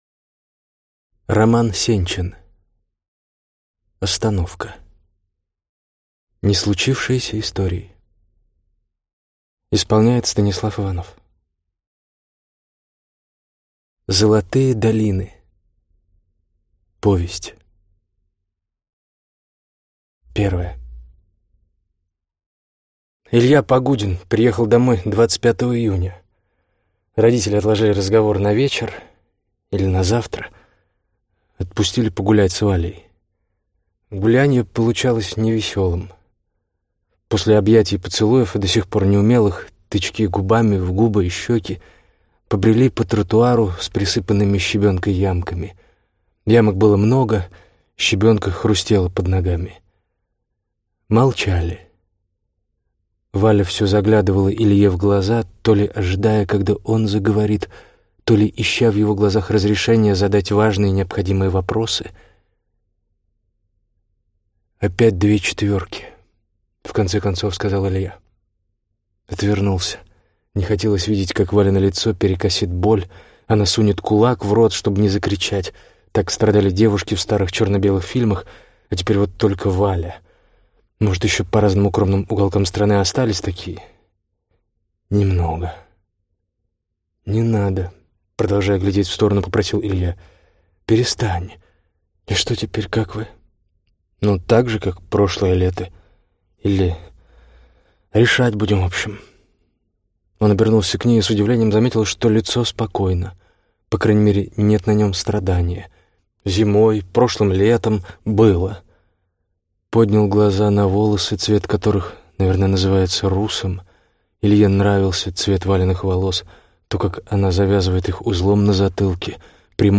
Аудиокнига Остановка. Неслучившиеся истории | Библиотека аудиокниг